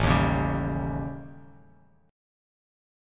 A7b5 chord